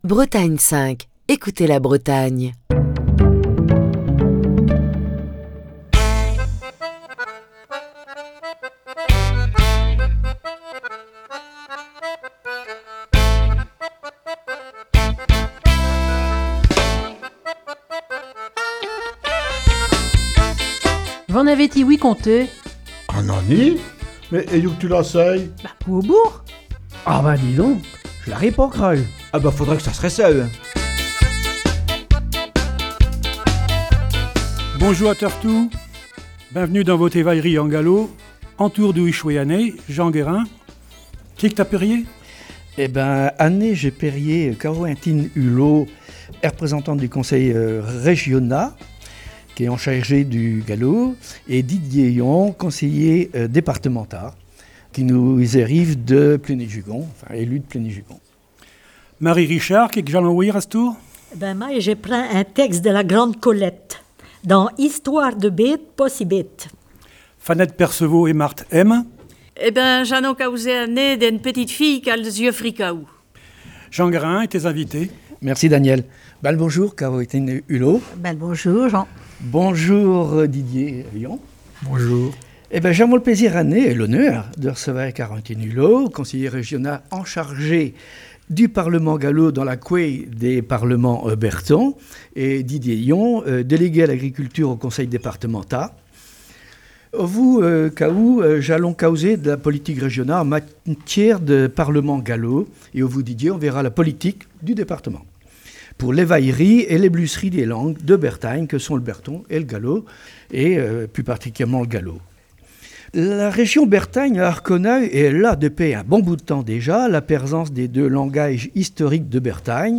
V'en avez ti-ouï conté, en direct depuis Ti Ar Vro Saint-Brieuc.